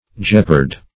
Jeopard \Jeop"ard\, v. t. [imp. & p. p. Jeoparded; p. pr. &